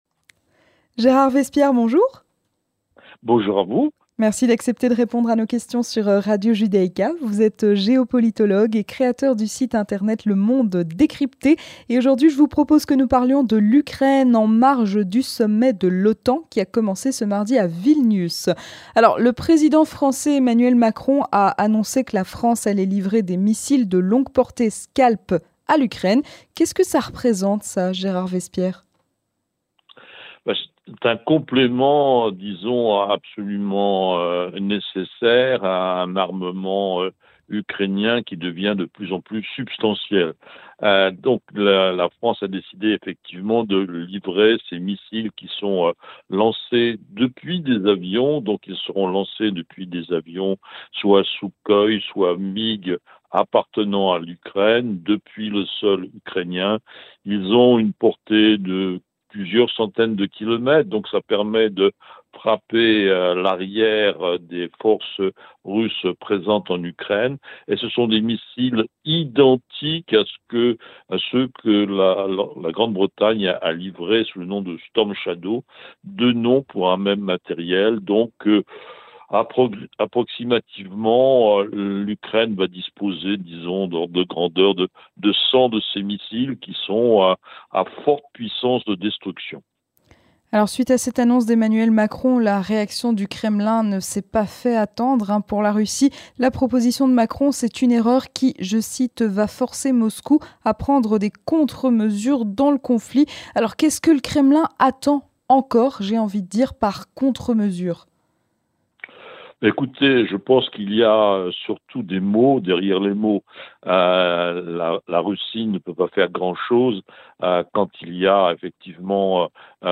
Entretien du 18h - Le sommet de l'OTAN à Vilnius